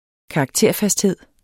Udtale [ -ˌfasdˌheðˀ ]